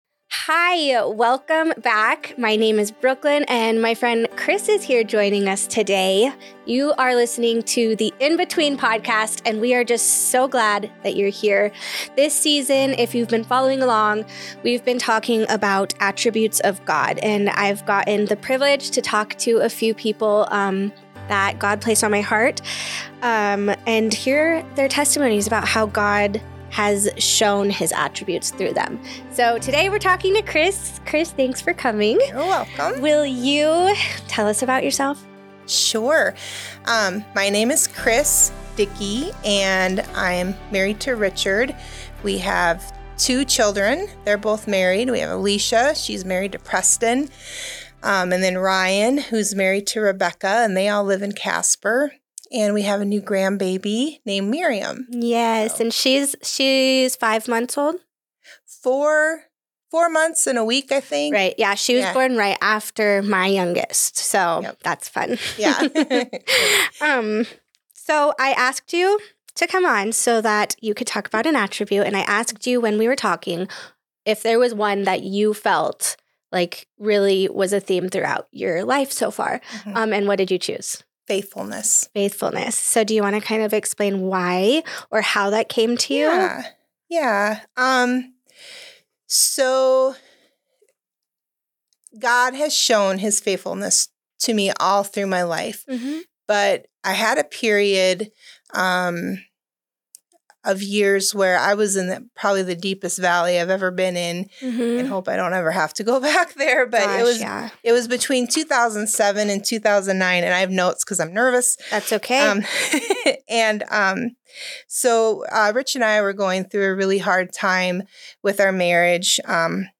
A raw, honest testimony for anyone walking through the valley.